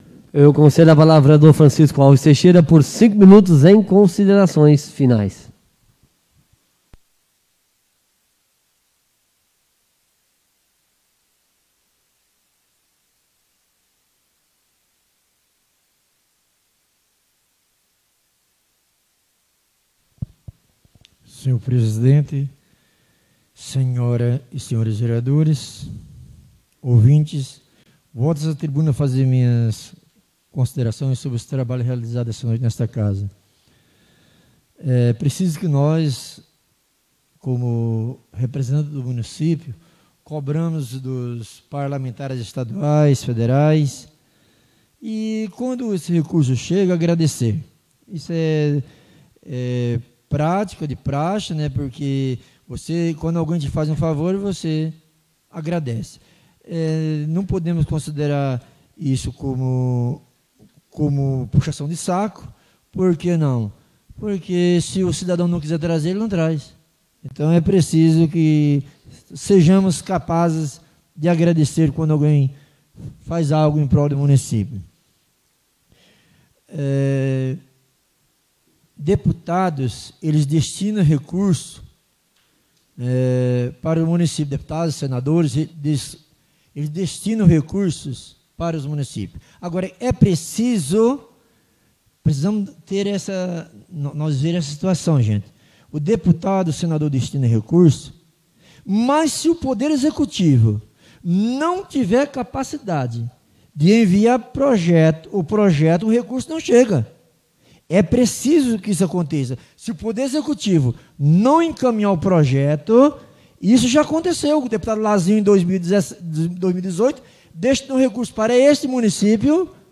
Oradores das Explicações Pessoais (22ª Ordinária da 4ª Sessão Legislativa da 6ª Legislatura)